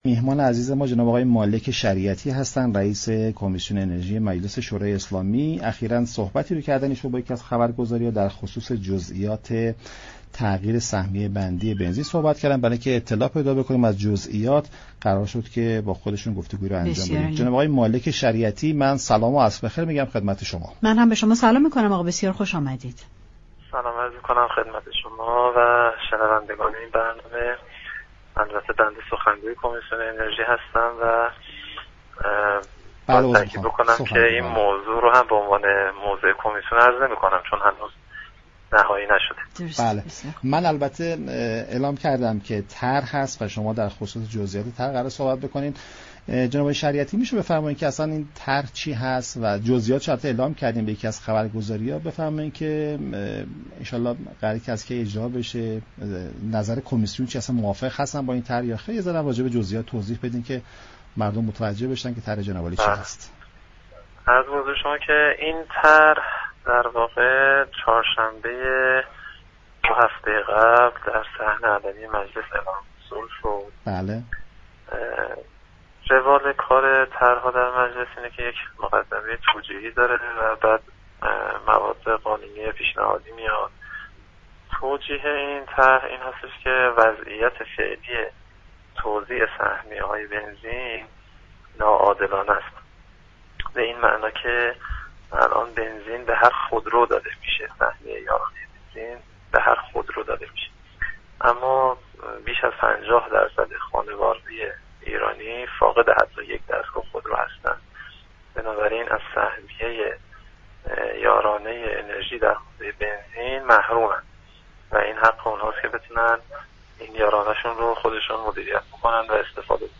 سخنگوی كمیسیون انرژی مجلس شورای اسلامی در گفتگو